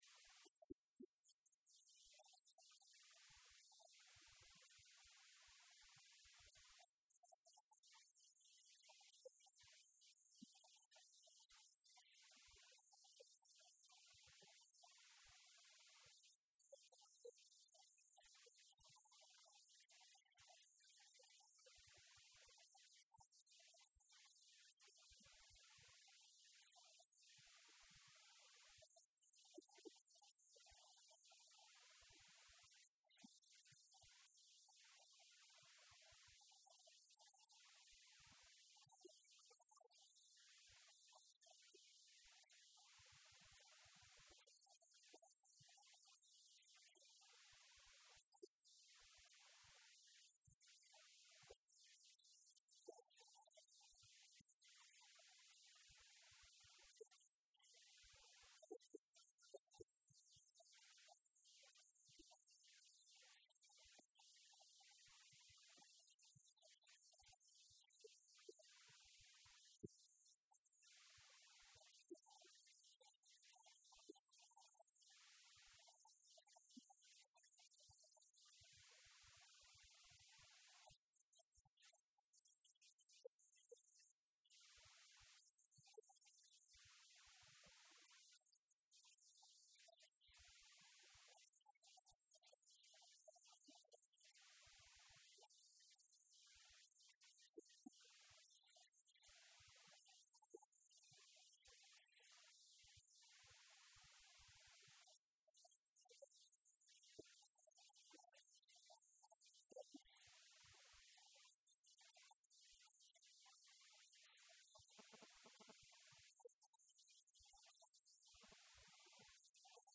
ICSL 40TH Annual General Meeting - Guest Speaker
PrimeMinisterHon.Dr.KennyD.AnthonySpeaksatICSL40thAGM.mp3